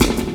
Snare Drum 67-12.wav